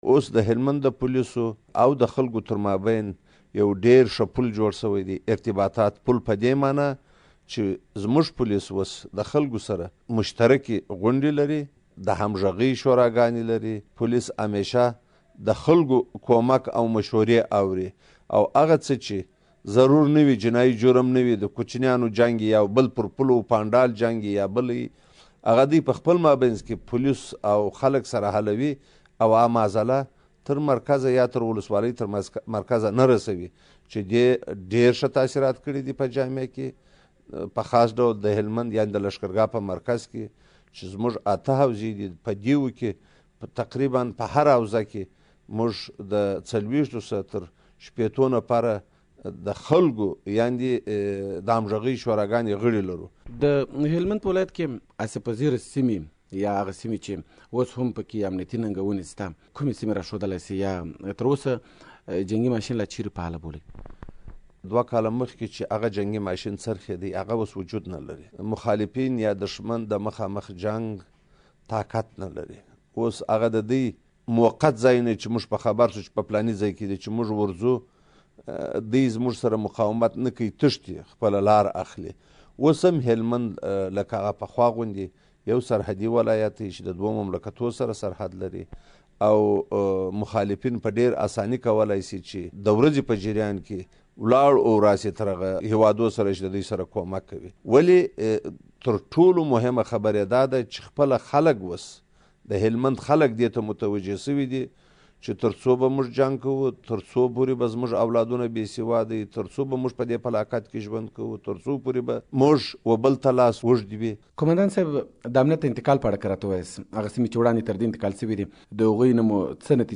د هلمند له امنيه قوماندان سره مرکه